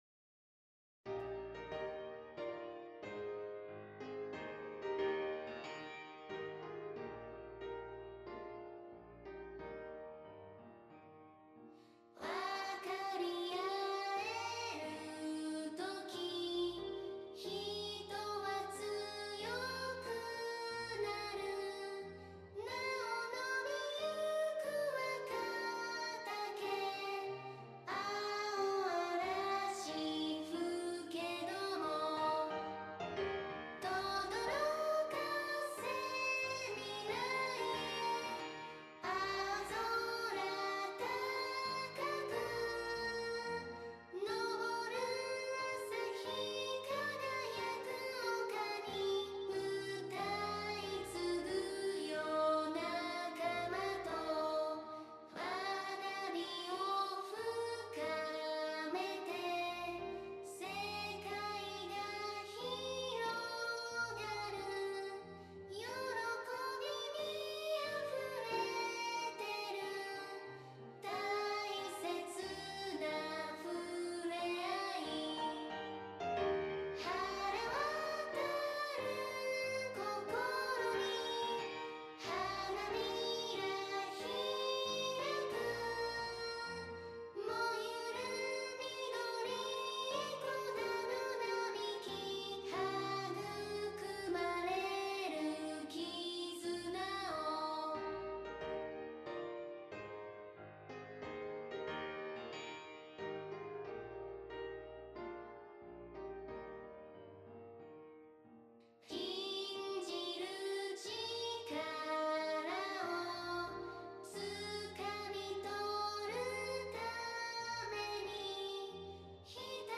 校　歌
小学生でも分かりやすい言い回しや、中学生向けのフレーズをバランスよく取り入れ、悩みや葛藤を表現しながらも明るく伸び伸びとした作風です。
音源データは、ボーカロイドに歌わせたポップス調（仮版）としてお聴きください。